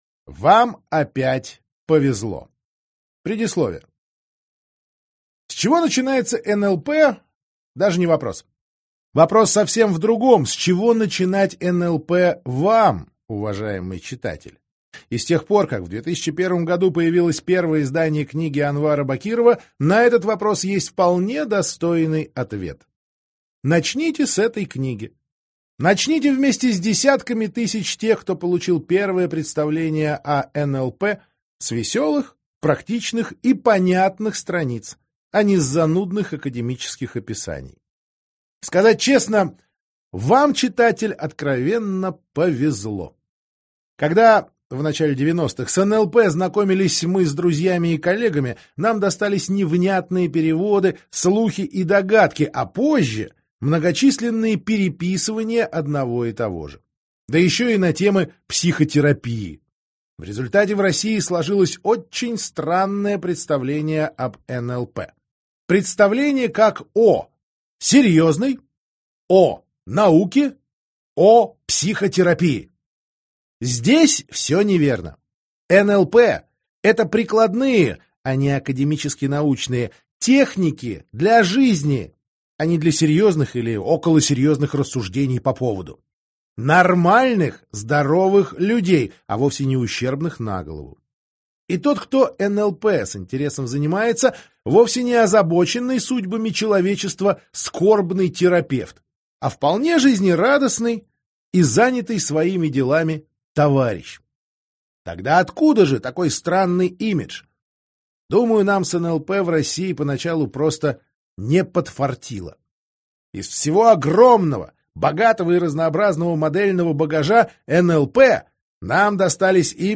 Аудиокнига Как управлять собой и другими с помощью НЛП | Библиотека аудиокниг